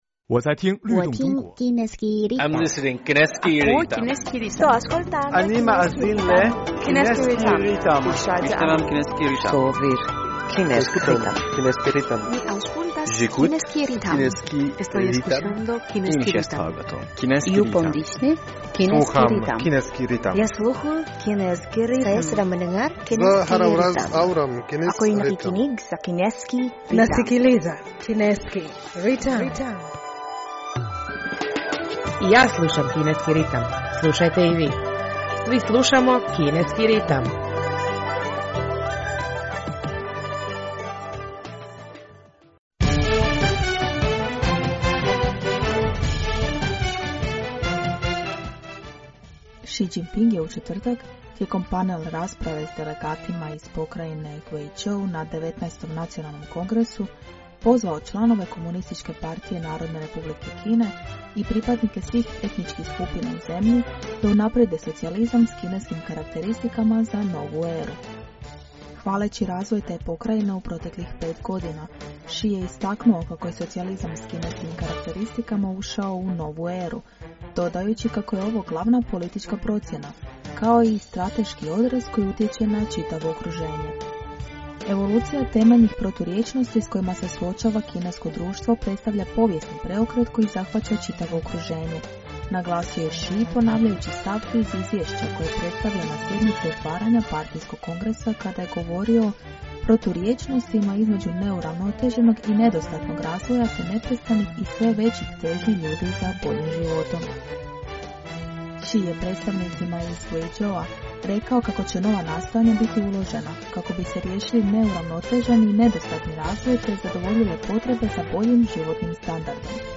U današnjoj emisiji prije svega poslušajte novosti iz Kine i svijeta, a zatim našu rubriku "U fokusu Kine".